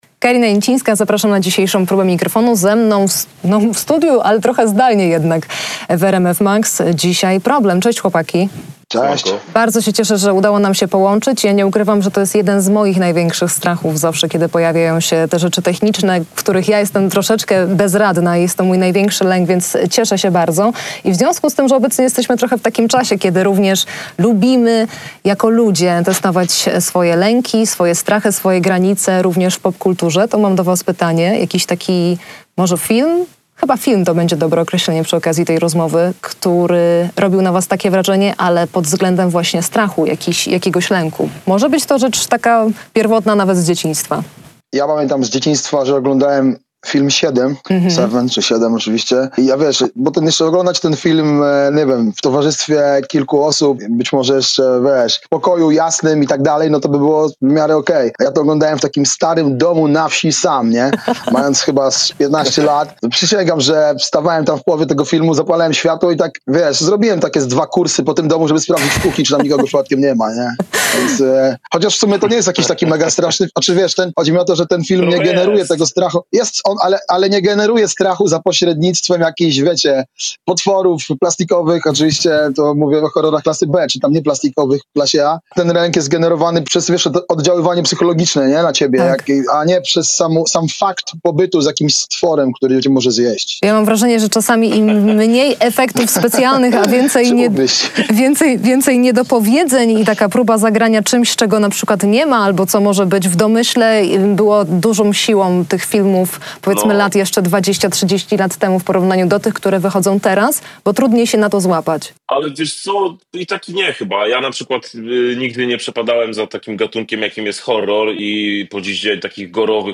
Zaśpiewali swój przedpremierowy numer